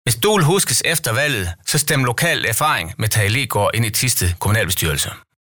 Radiospot: